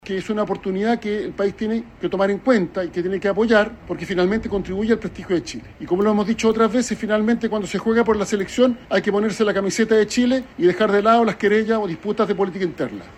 En esa línea, el ministro del Interior, Álvaro Elizalde, sostuvo que la candidatura de Bachelet debiera abordarse con una mirada país y llamó a dejar atrás las disputas internas.